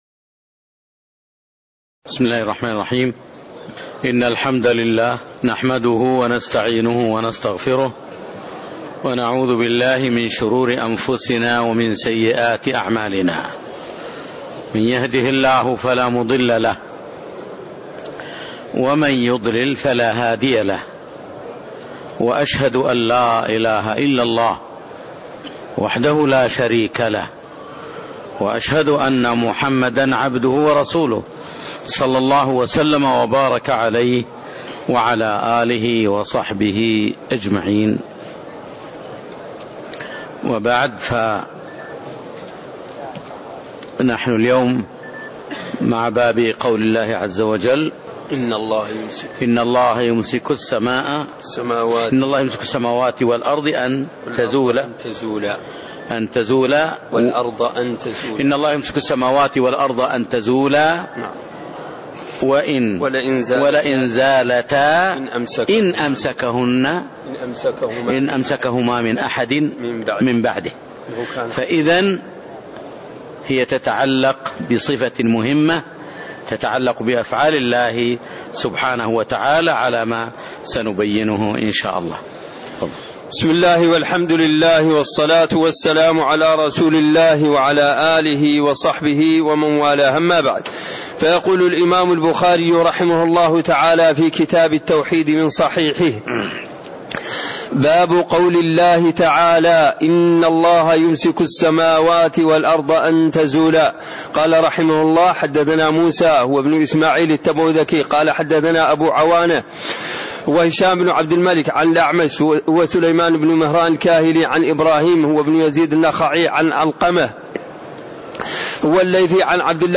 تاريخ النشر ٢٥ شوال ١٤٣٤ هـ المكان: المسجد النبوي الشيخ